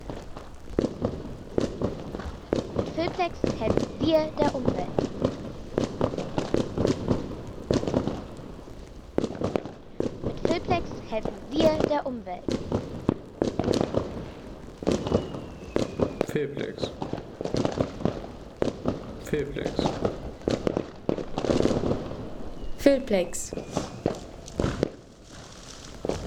Knallender Jahreswechsel
Knallender Jahreswechsel in Flensburg – Silvester 2017 in Gartenstad ... 3,50 € Inkl. 19% MwSt.